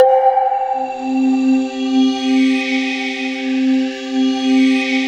Index of /90_sSampleCDs/USB Soundscan vol.13 - Ethereal Atmosphere [AKAI] 1CD/Partition D/04-ACTUALSYN